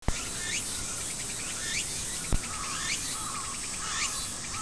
ツクツクボウシの声(101KB wave)